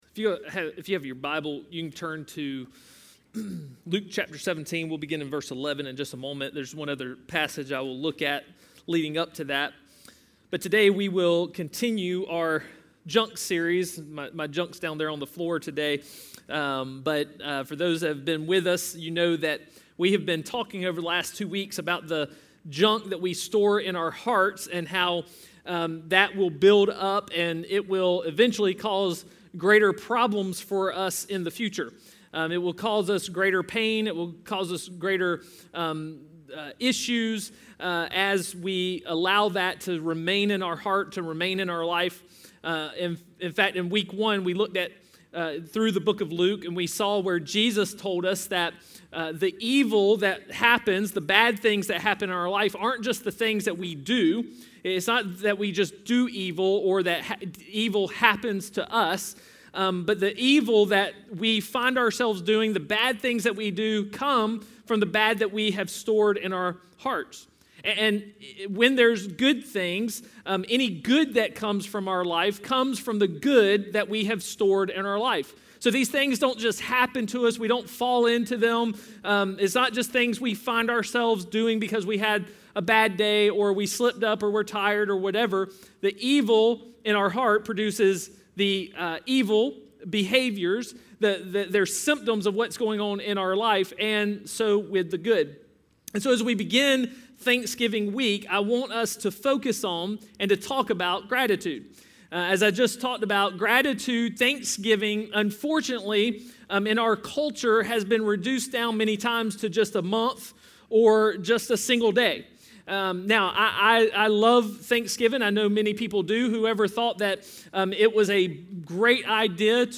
A message from the series "Junk."